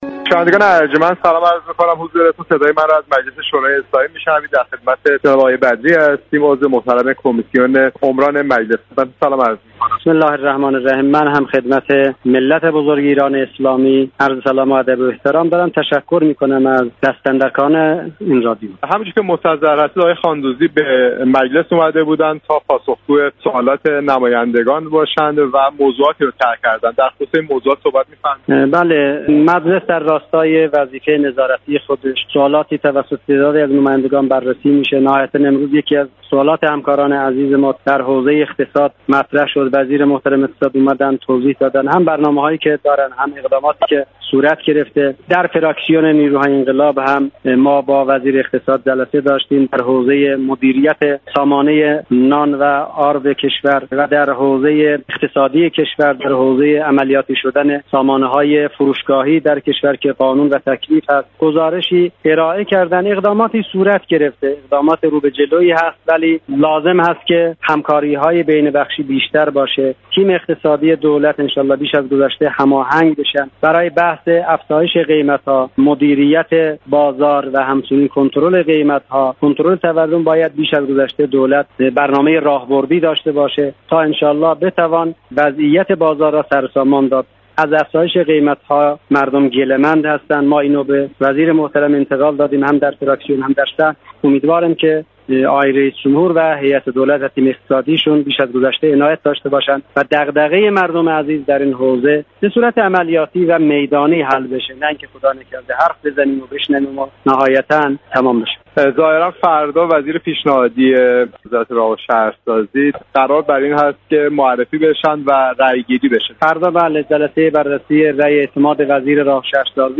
بدری عضو كمیسیون عمران مجلس شورای اسلامی در گفت و گو